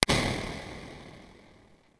Concertzaal
Geluidimpuls in een lege concertzaal.
Impulse_hall2.wav